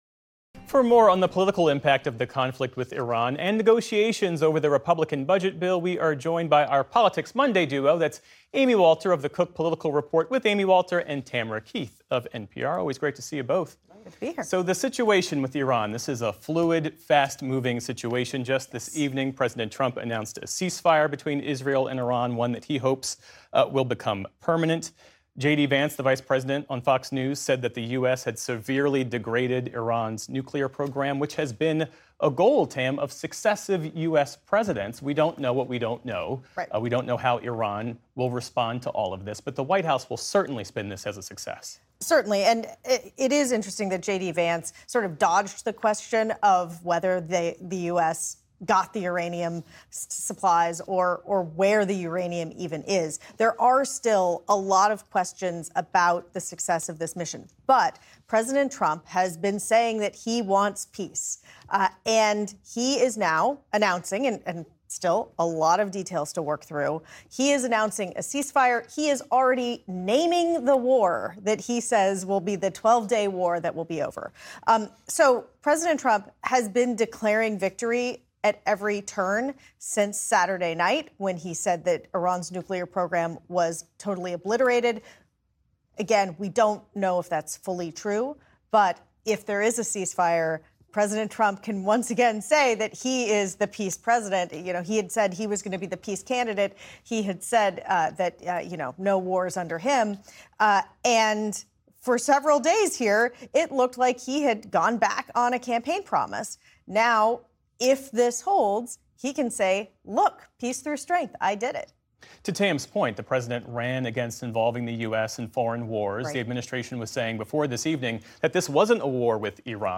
NPR’s Tamara Keith and Amy Walter of the Cook Political Report with Amy Walter join Geoff Bennett to discuss the latest political news, including the political impact of the conflict with Iran and negotiations over the Republican budget bill.